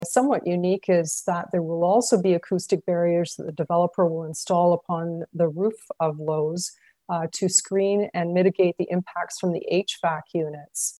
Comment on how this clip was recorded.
At Monday night’s meeting of the city’s Planning Committee